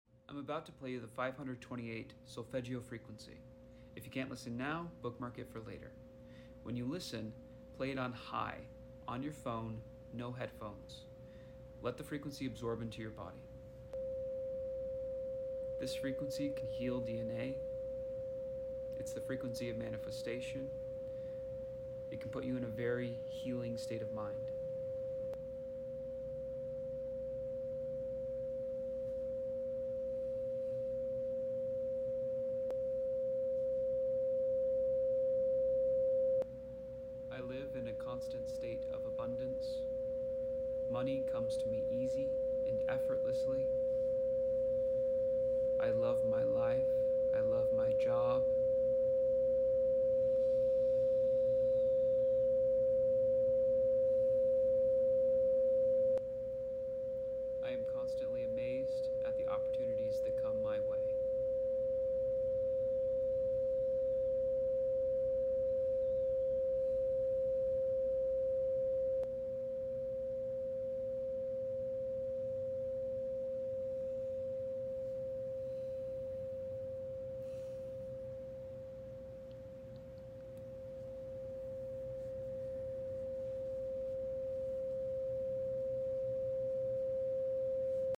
Affirmations 528hz Healing frequency. Energize your core being.